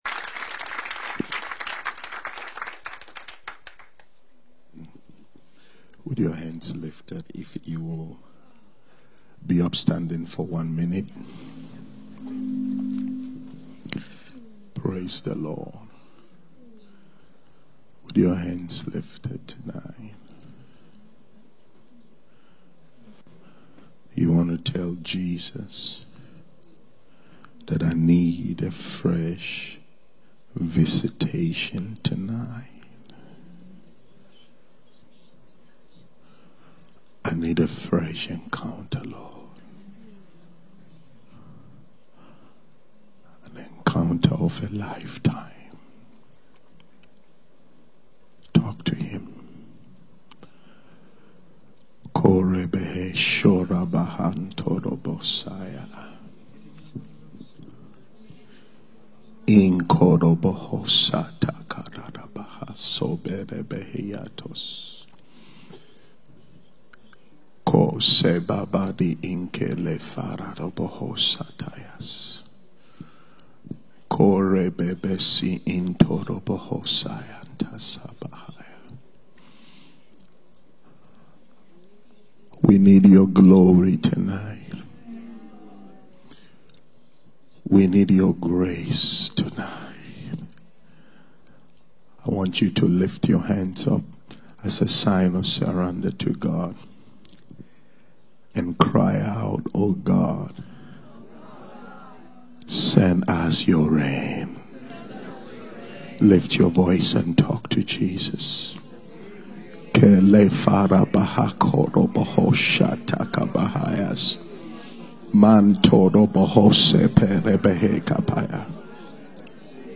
The prophet imparts a profound and captivating message on the restoration of divine glory. During this sacred gathering, God’s ministered through the prophet, manifesting in extraordinary, precise, and mighty prophetic ministrations.